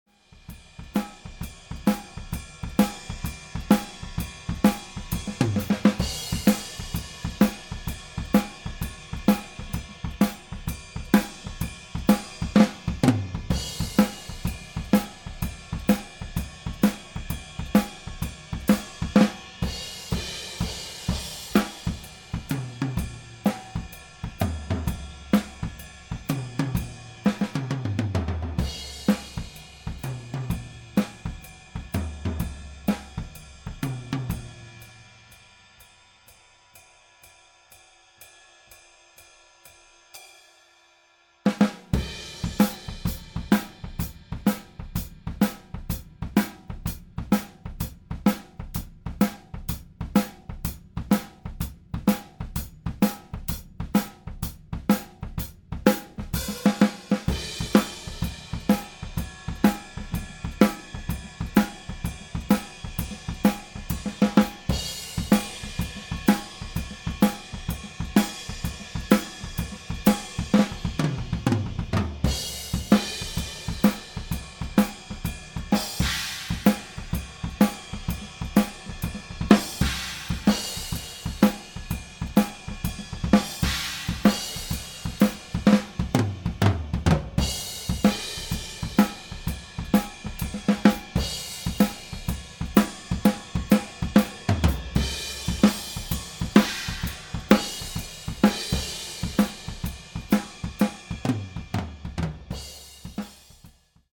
Edit: OHs pur und ohne Schminke:
MKH40 OH (Glyn Johns) - unbearbeitet ohne EQ, ohne Kompression
OH_MKH40_flat.MP3